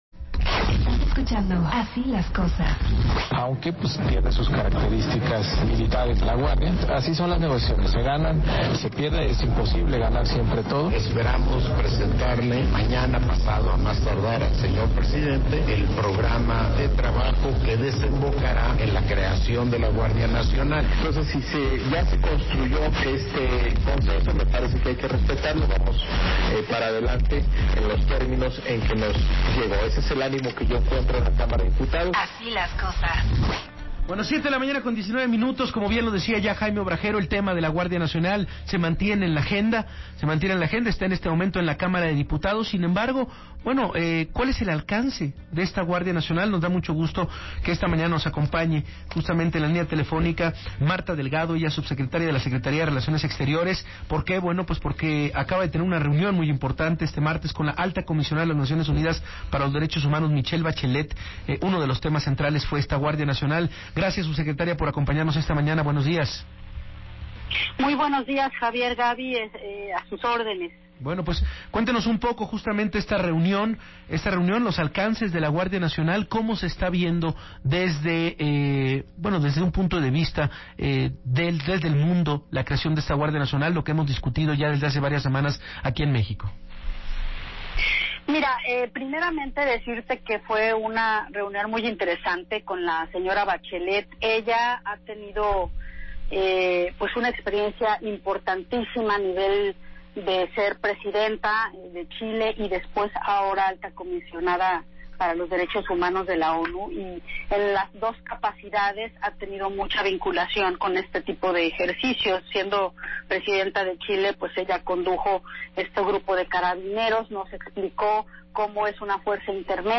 El 27 de febrero de 2019, Martha Delgado, subsecretaria para Asuntos Multilaterales y Derechos Humanos de la Secretaría de Relaciones Exteriores platicó en entrevista sobre su reunión con la Alta Comisionada de Derechos Humanos de Naciones Unidas, Michelle Bachelet en Ginebra.